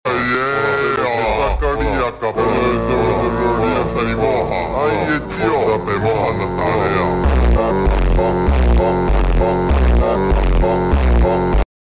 glowing aura skull☠ sound effects free download